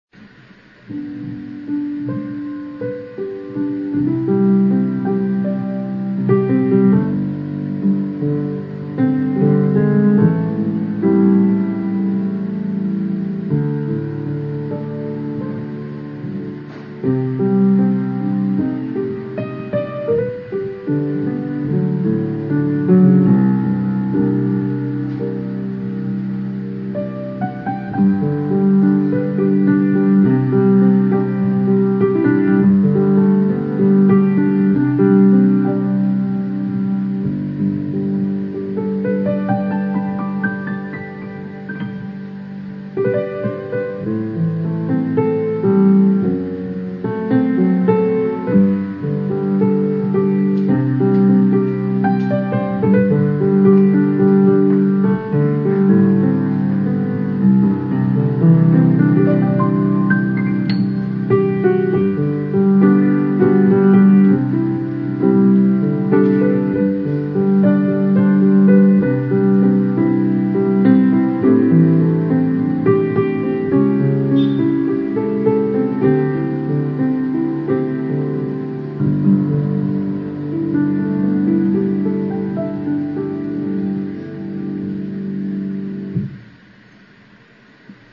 A short piece I played at Herman Brood's piano some years ago, and a rock-like S90 multirecording, recorded in a short time.